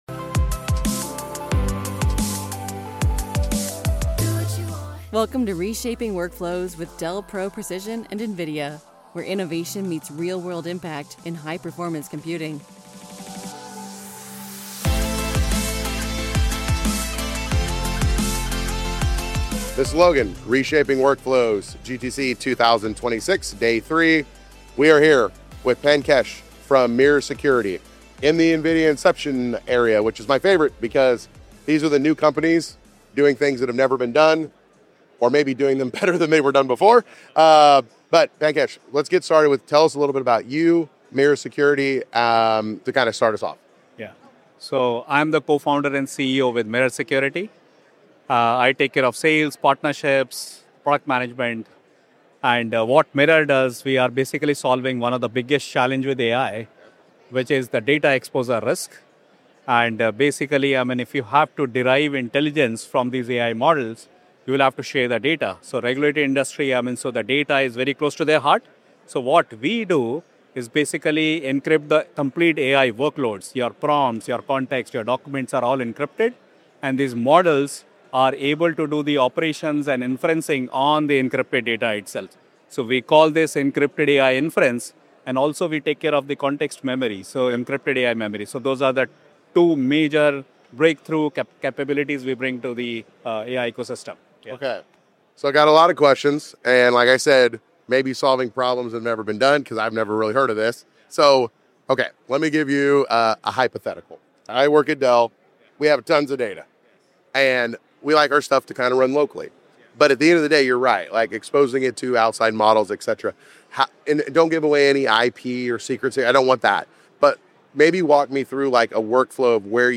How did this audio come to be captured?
Every AI interaction is a potential data leak — unless you fix it. Live from GTC